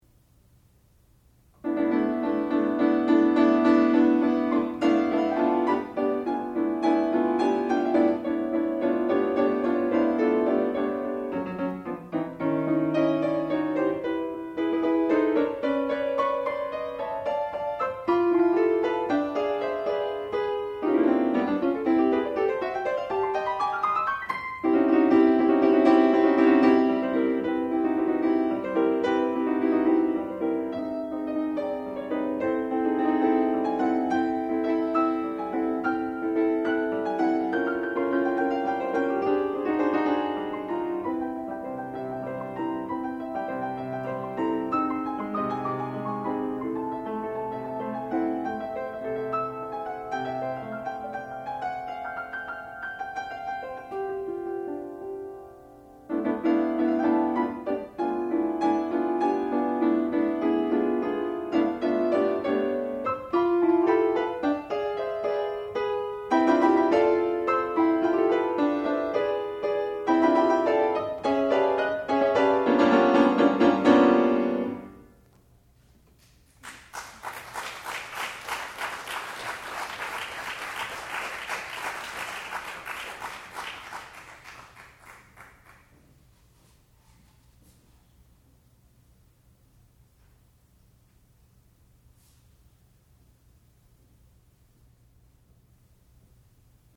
sound recording-musical
classical music
piano